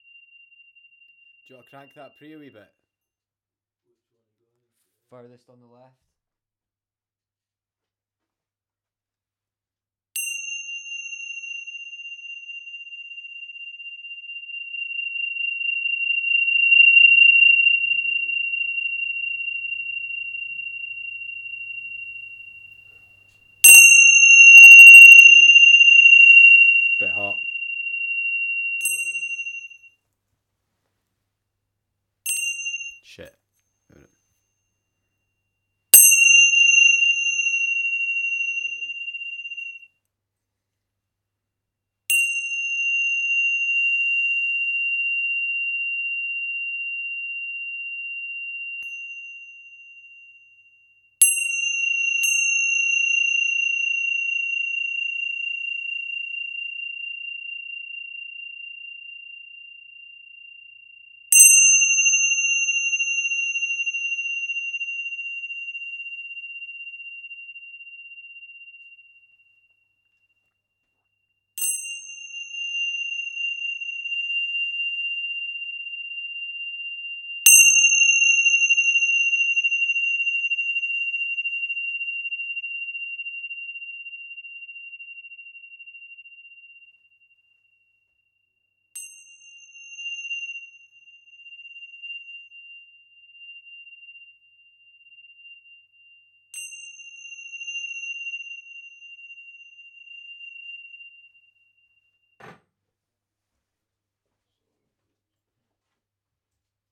Tibetan Bells 192kHz Original
bell clang ding down iron metal metallic ping sound effect free sound royalty free Sound Effects